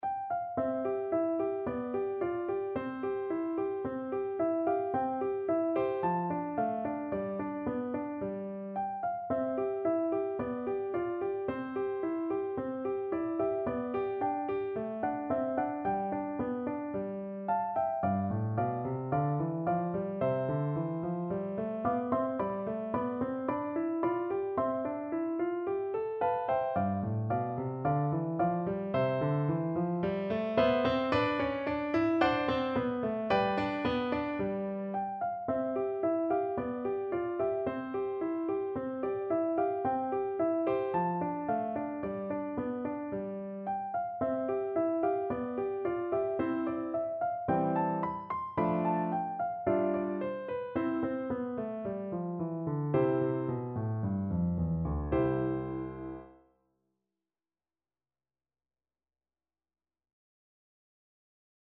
No parts available for this pieces as it is for solo piano.
4/4 (View more 4/4 Music)
~ = 110 Moderato
Piano  (View more Easy Piano Music)
Classical (View more Classical Piano Music)